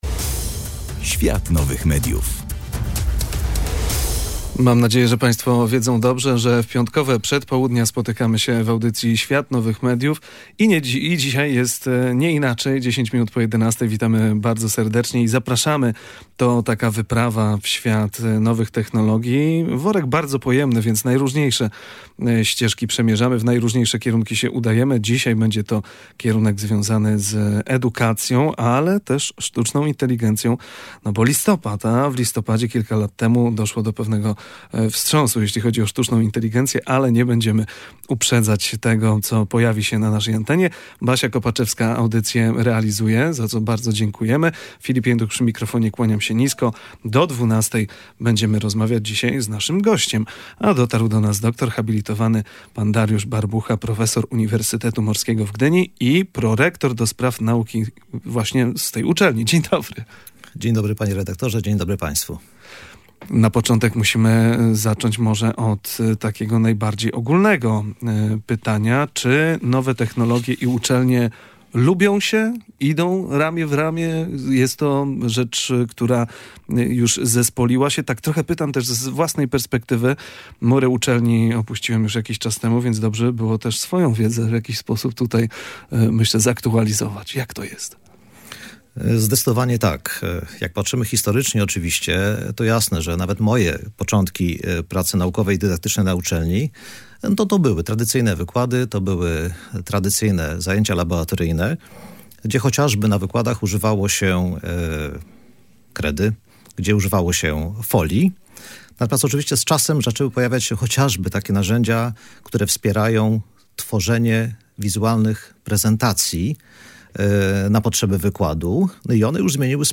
W audycji „Świat Nowych Mediów” rozmawialiśmy o tym, jak nowe technologie zmieniają współczesną edukację.